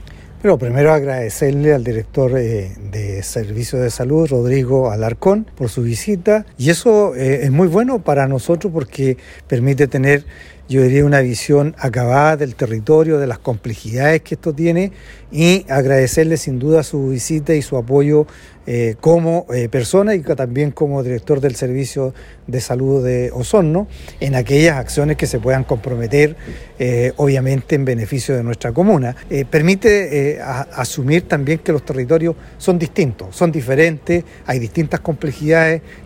Por su parte, el Alcalde Bernardo Candia subrayó que esto permite a las autoridades tener una visión acabada del territorio de San Juan de la Costa y de sus complejidades.